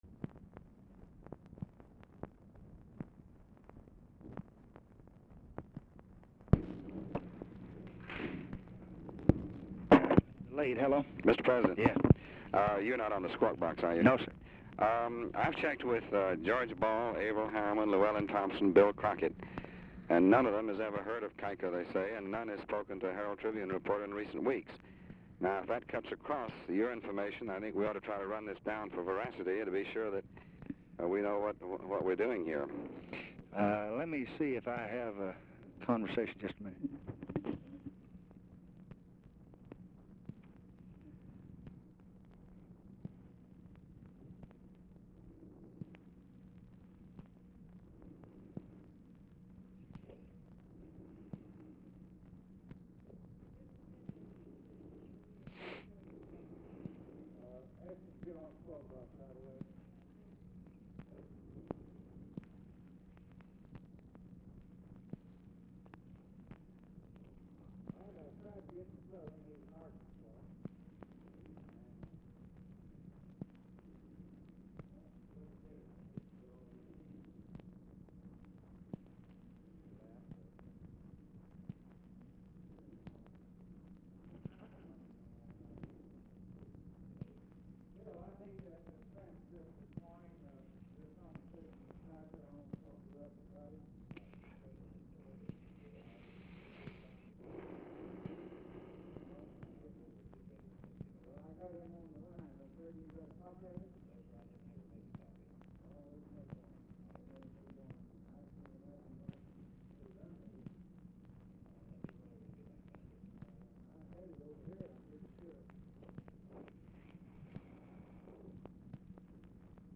Telephone conversation
LBJ TALKS TO UNIDENTIFIED PERSON IN OFFICE FOR MORE THAN 1:00 ABOUT LOCATING TRANSCRIPT WHILE KEEPING RUSK ON THE LINE
Format Dictation belt
Oval Office or unknown location